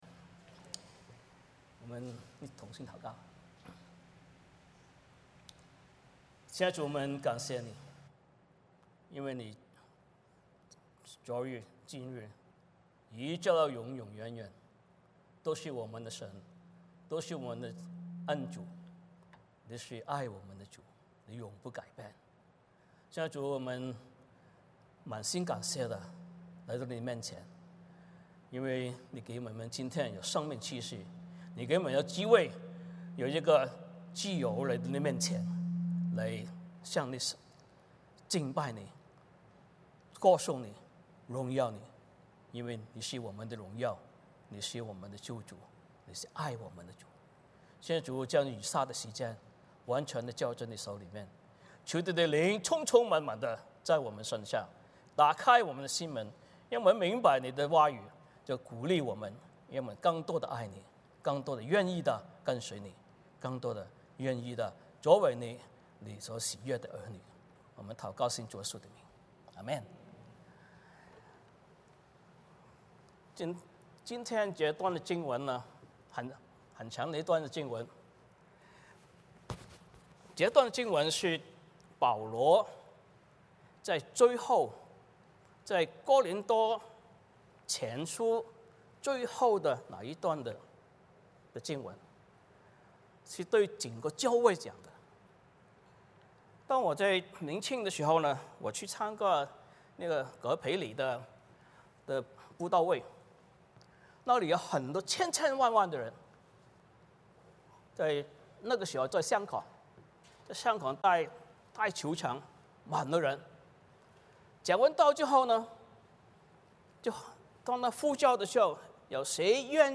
欢迎大家加入我们国语主日崇拜。
6-28 Service Type: 圣餐主日崇拜 欢迎大家加入我们国语主日崇拜。